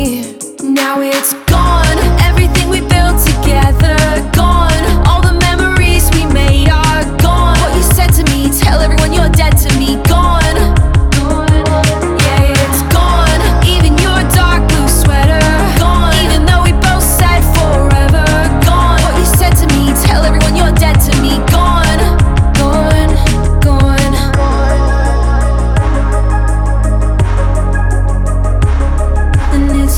Жанр: Поп / Инди / Альтернатива